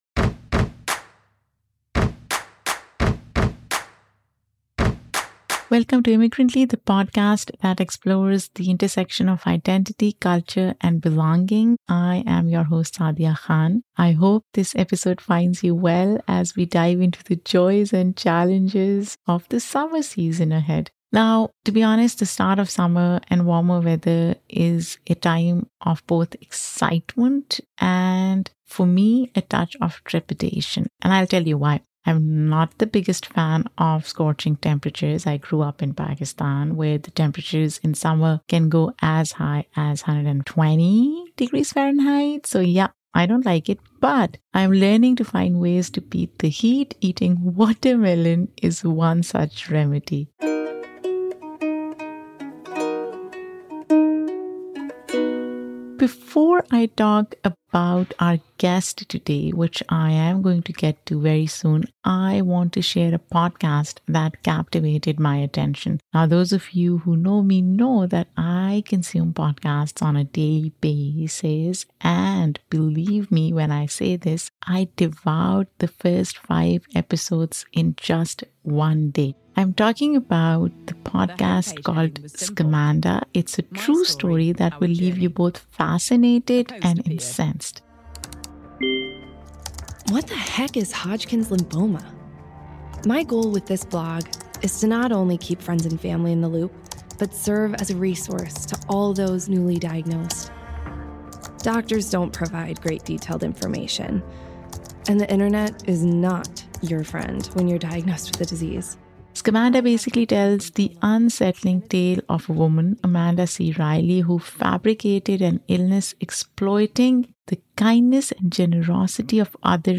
We also discover the inspirations that have shaped her creative path and fueled her passion for storytelling. So sit back, relax, and get ready for an engaging and laughter-filled episode.